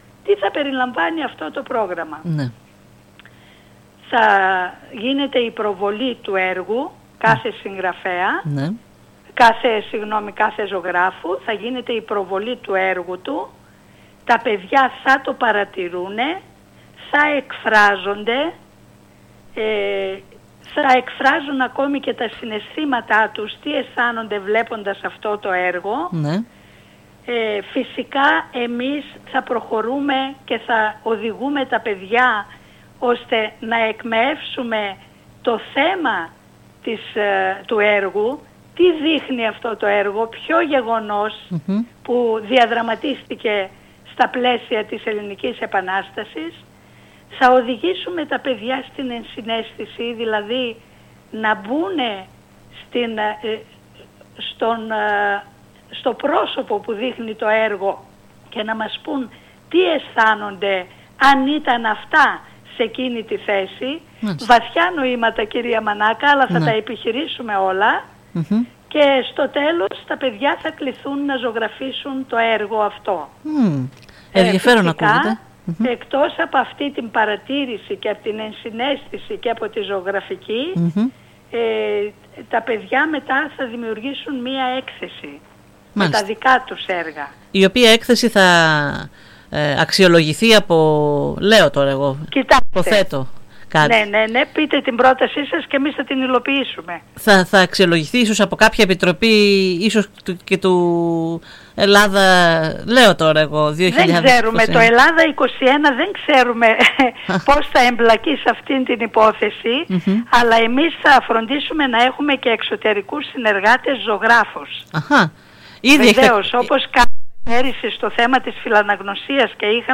Λεπτομέρειες του προγράμματος έδωσε σήμερα μιλώντας στη ΕΡΤ Ορεστιάδας